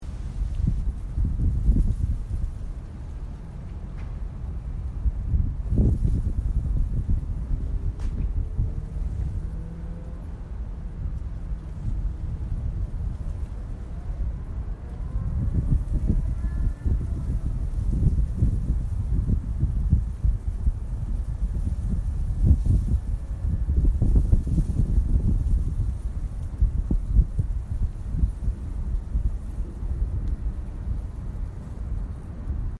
Field Recording 7
The pathway outside the student center.
You hear the wind rustling threw the tall grass, some water hitting the pathway, and the wind blowing into the microphone.